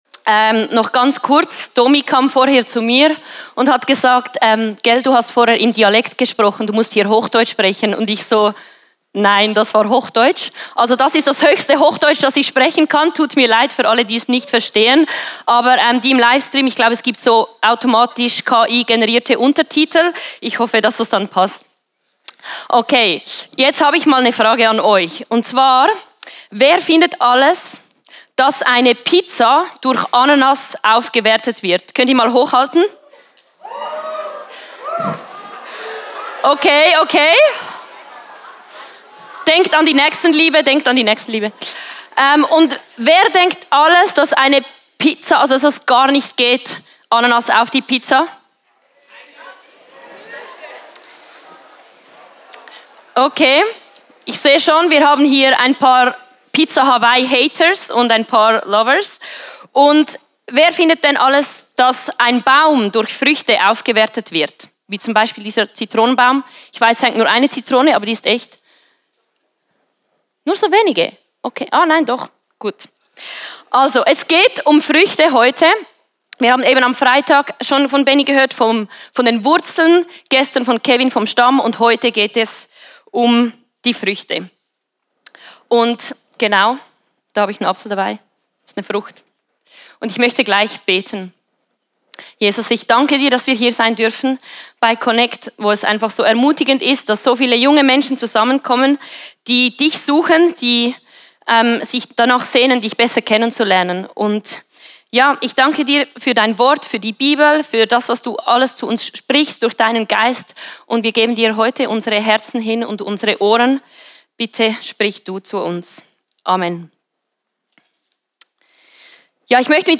Der Connect Jugendgottesdienst in diesem Jahr steht unter dem Motto "Rooted".
Predigt als Audio